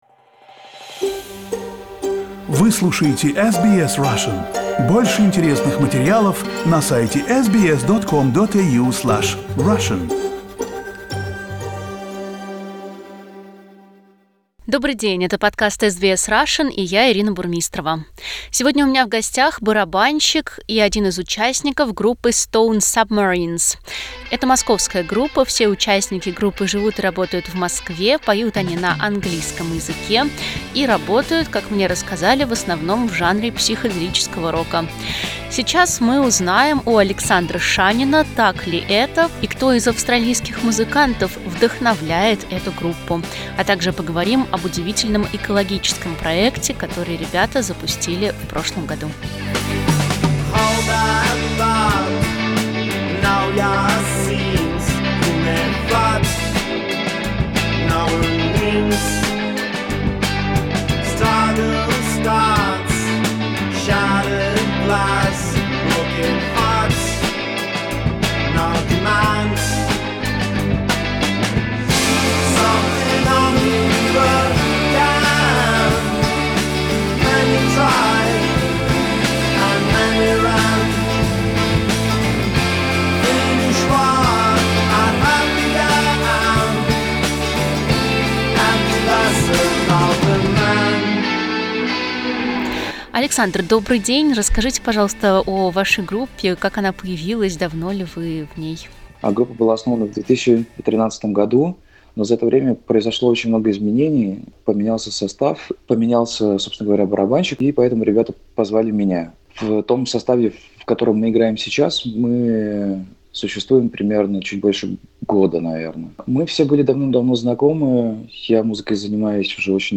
Вот о чем он рассказывает в интервью: почему ребята записывают песни только на английском и может ли музыка одинаково гармонично звучать на разных языках; как Австралия сменила Англию в качестве столицы нового качественного рока; о своих любимых австралийских группах; как участники 'Stone Submarines' привлекают внимание к экопроблемам, и др.